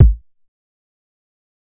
Kick 1.wav